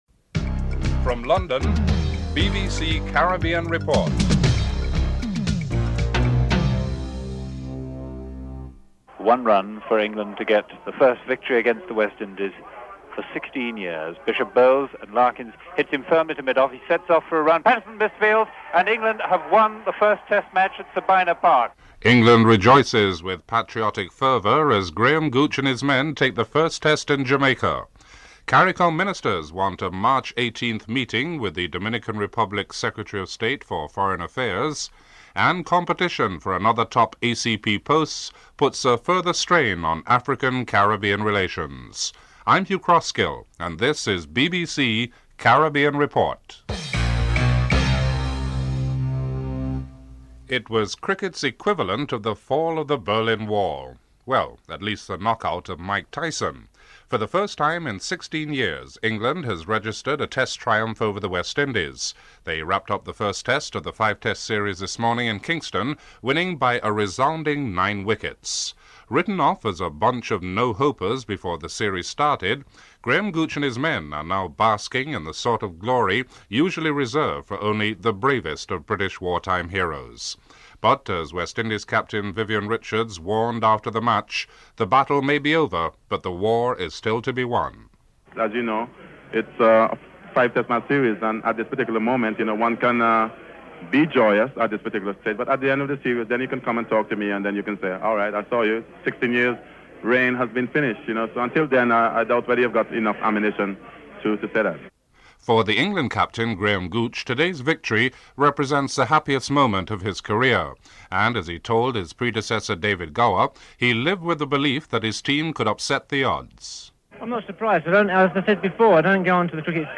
Includes a musical interlude at the beginning of the report.